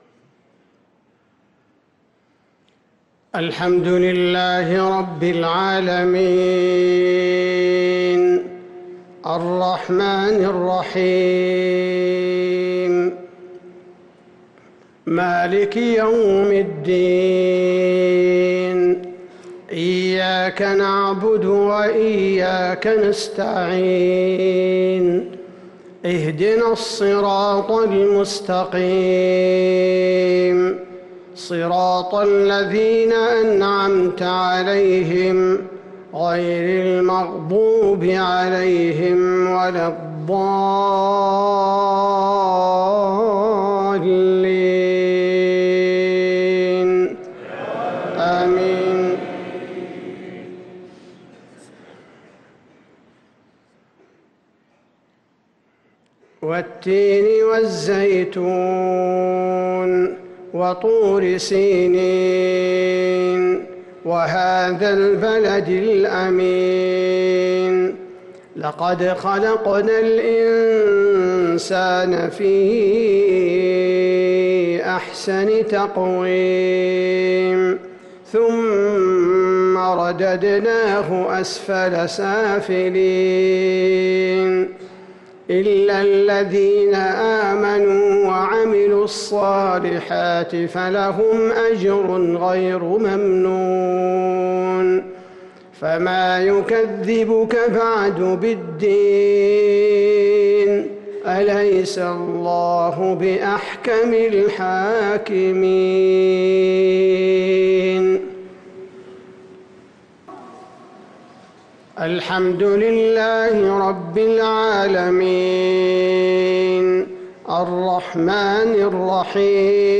صلاة المغرب للقارئ ماهر المعيقلي 21 ربيع الأول 1445 هـ
تِلَاوَات الْحَرَمَيْن .